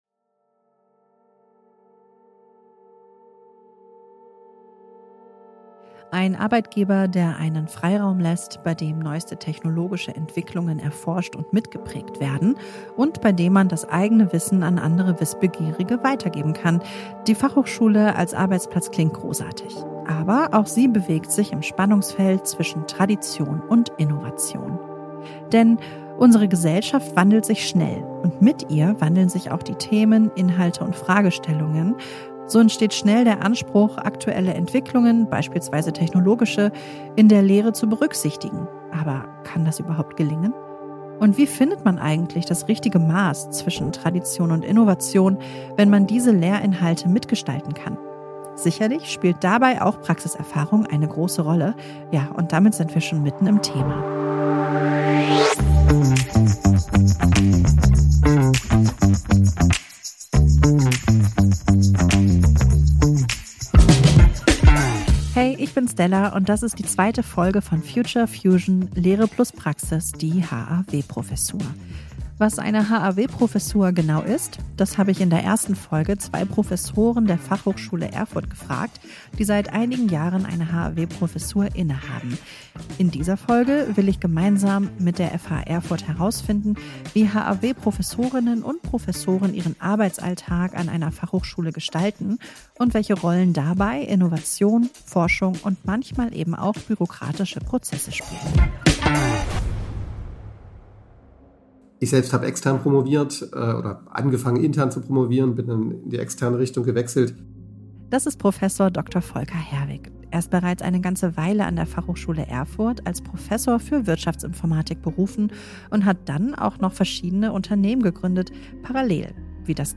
Sie diskutieren, wie Tradition und Innovation in ihrem Berufsfeld zusammengeführt werden können, welche Potenziale sich daraus ergeben und welche Grenzen existieren. Zudem reflektieren sie, was sie von ihren Studierenden in diesem Kontext lernen.